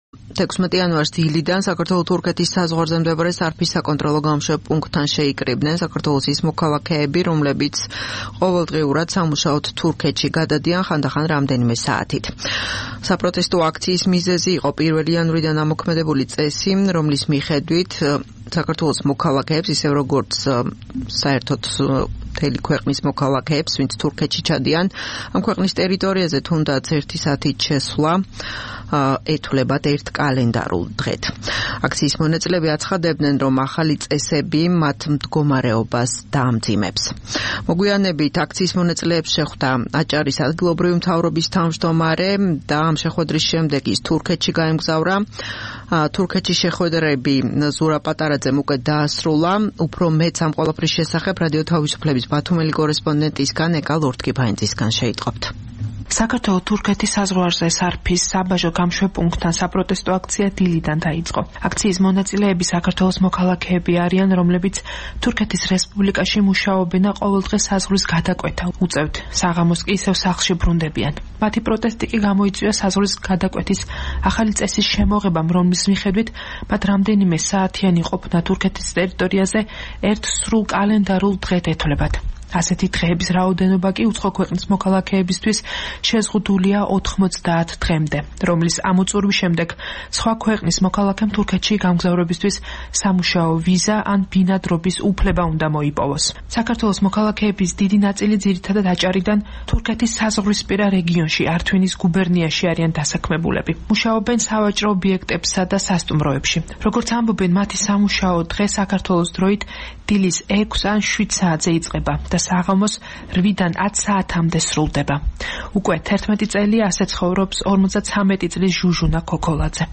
საპროტესტო აქცია სარფის სასაზღვრო-გამშვებ პუნქტთან